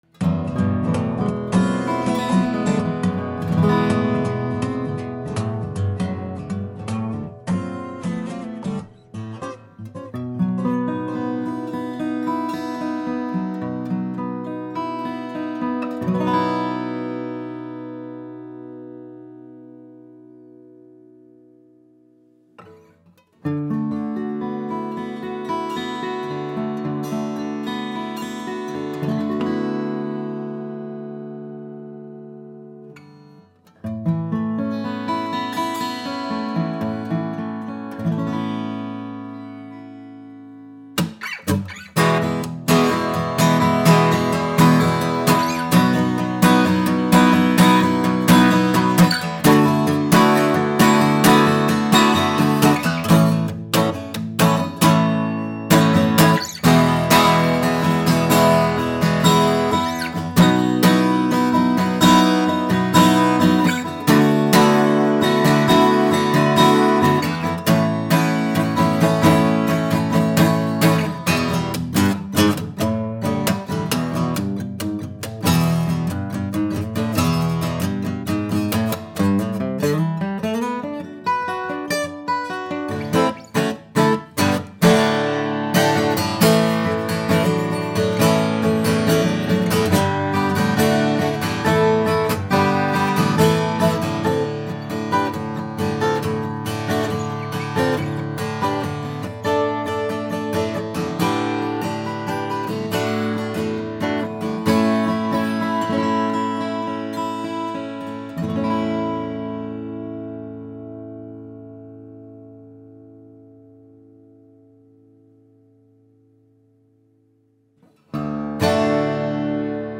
Beautiful Santa Cruz D/PW Custom ‘Bryn Anthem’ build with 1800s ‘Bryn Anthem’ Indian Rosewood and Mastergrade German Spruce offering that balance, pure and refined tone with those crystalline mids and highs that we all love.
Clear treble, strong but not boomy bass, and a sweet, singing midrange. The combination produces rich overtones and a “shimmer” that develops with age and playing.
Santa-Cruz-D-Pre-War.mp3